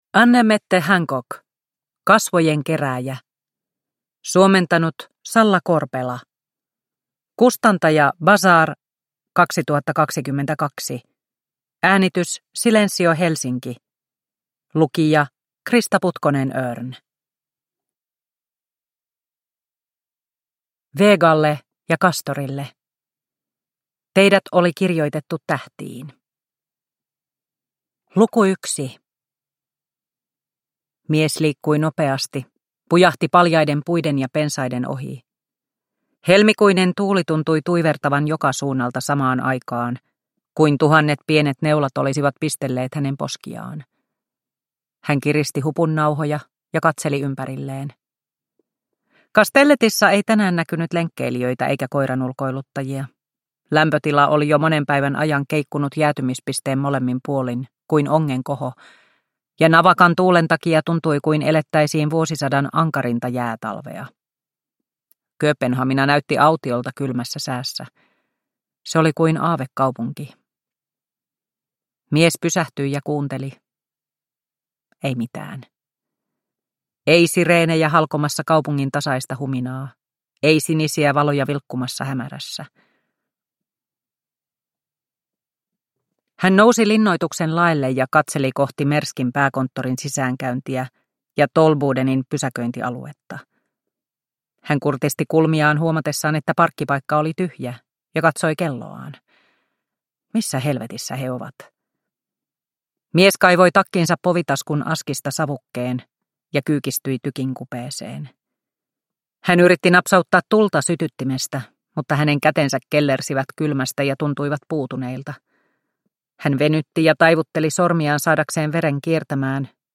Kasvojenkerääjä – Ljudbok – Laddas ner